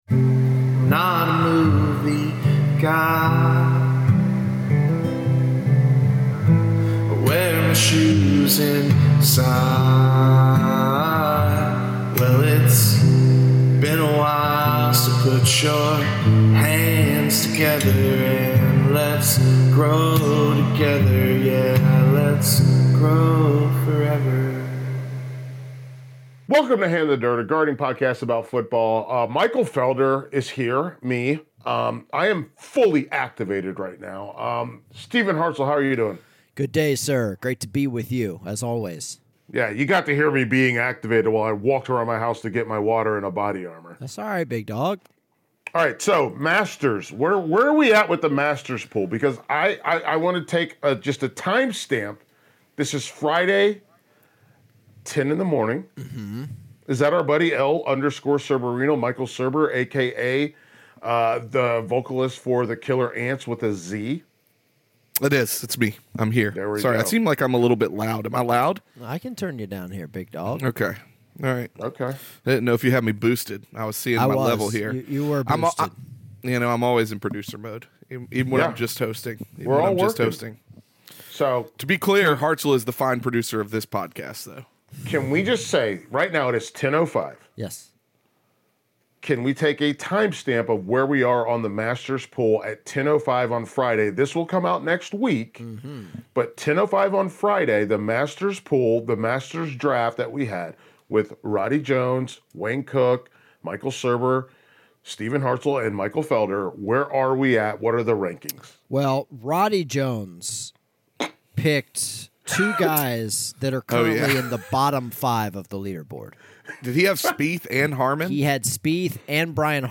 we take your calls on running questions